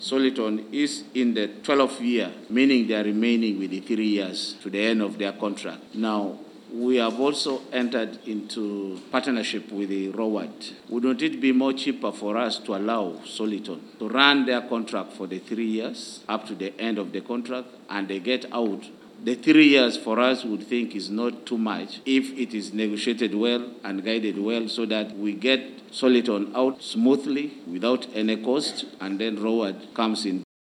The Minister made the revelation while leading entities under the Ministry to present the Ministerial Policy Statement to the Parliament Committee on ICT and National Guidance on Thursday, 03 April 2025.
Hon. Tony Ayoo, ICT Committee Chairperson.mp3